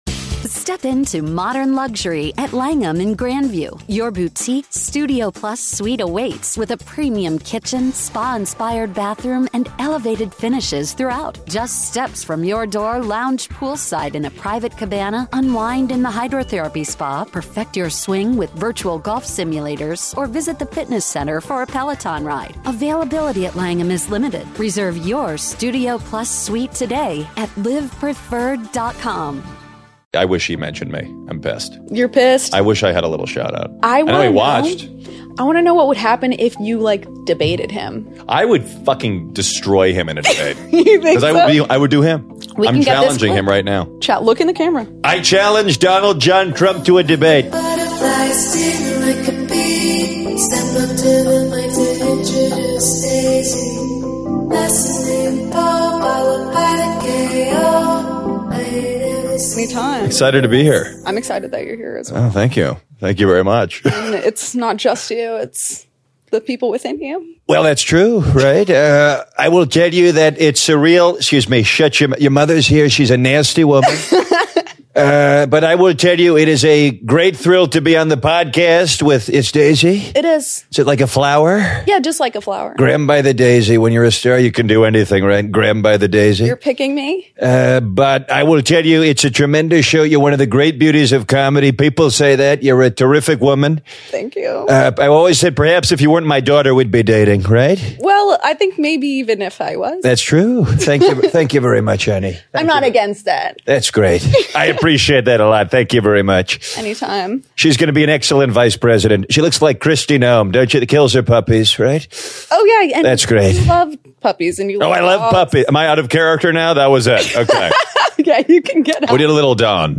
Matt Friend graces the show with his impeccable impersonations, gives background on his rise to fame as a mainstream comedian, discusses the White House Correspondence Dinner, and challenges a Presidential Candidate to a debate.